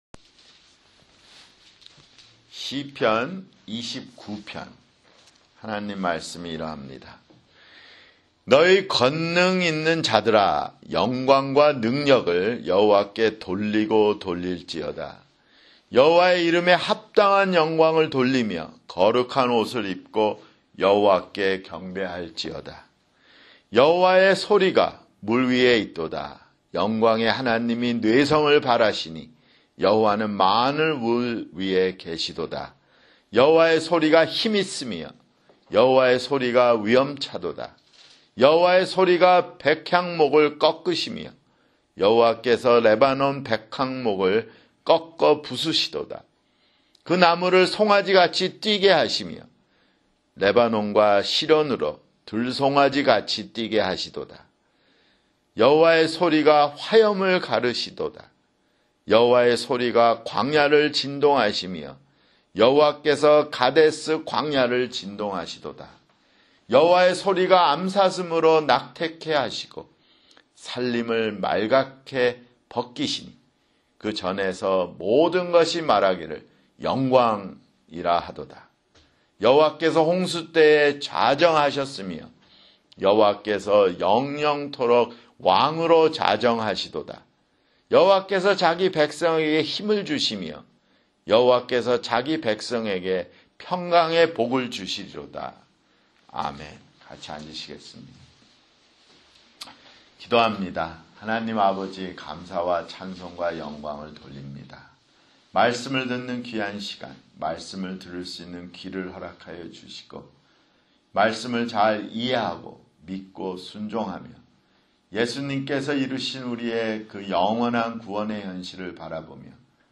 [주일설교] 시편 (22)